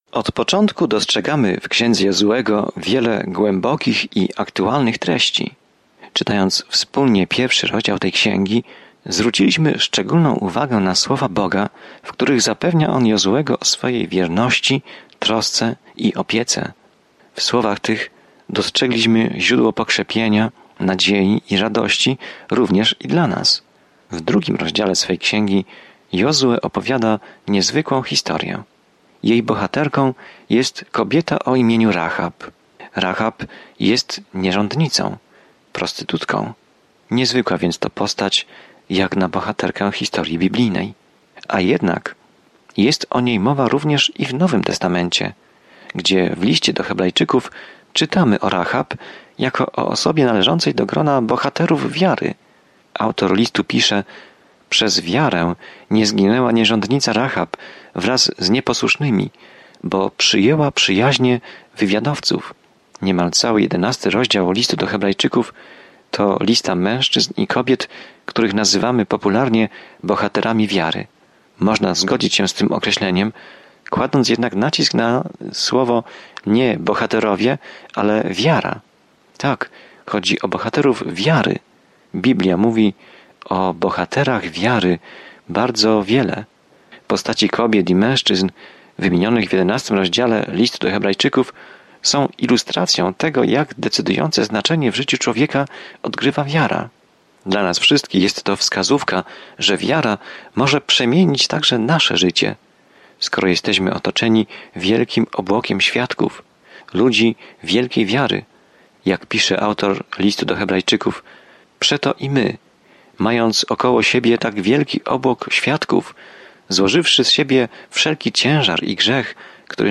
Pismo Święte Jozuego 2 Dzień 2 Rozpocznij ten plan Dzień 4 O tym planie Nazwijmy Księgę Jozuego „Wyjścia: część druga”, gdy nowe pokolenie ludu Bożego zajmie ziemię, którą im obiecał. Codziennie podróżuj przez Jozuego, słuchając studium audio i czytając wybrane wersety ze słowa Bożego.